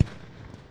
fireworks